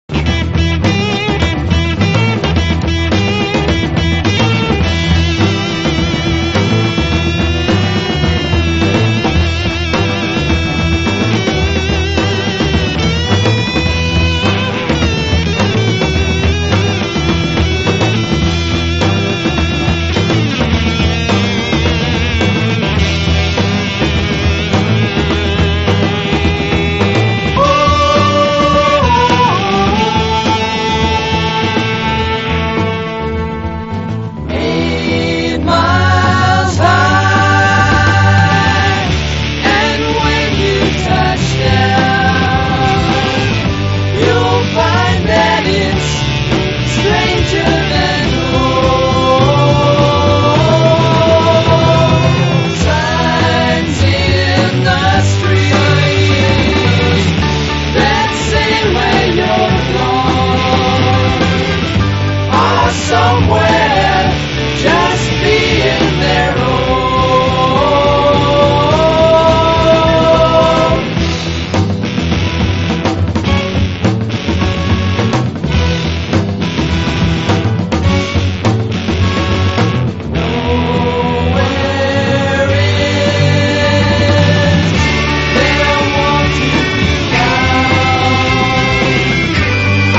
サイケな轟音ファンキー・ロックの連発に血湧き肉躍る1ST！
カナダ産ブラス・ロック/ジャズ・ロック・バンド
荒々しい轟音ファンキー・ロック